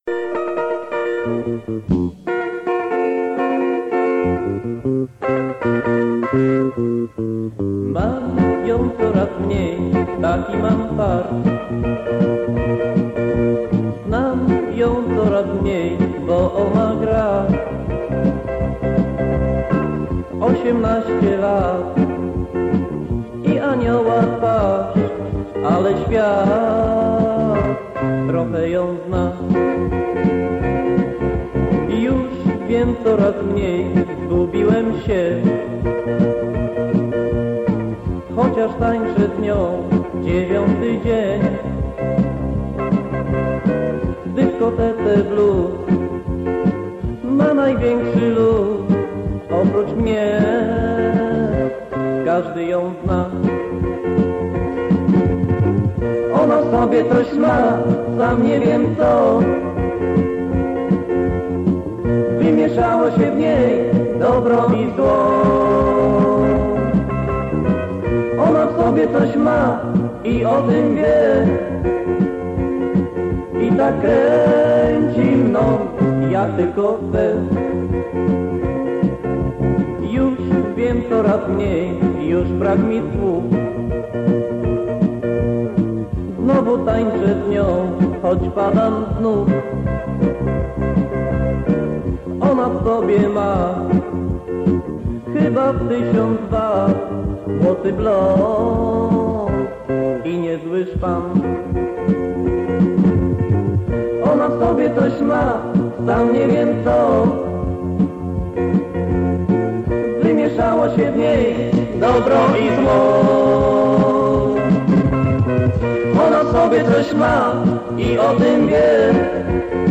Utwór z kasety magnetofonowej mojego znajomego.